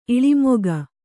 ♪ iḷimoga